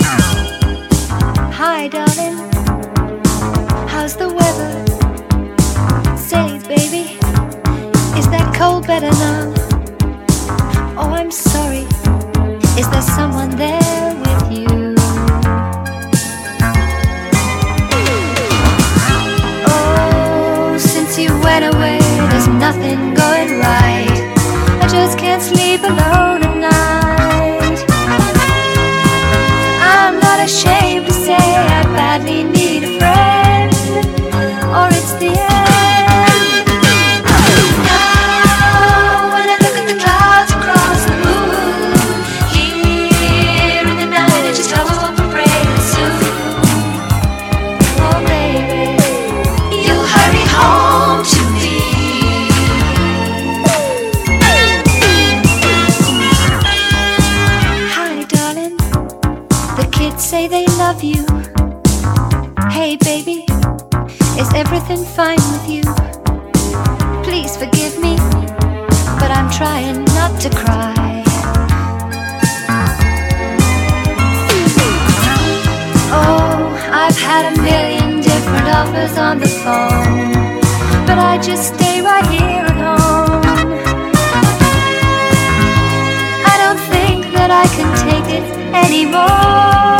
HIP HOP/R&B / NEW WAVE / R&B / UK SOUL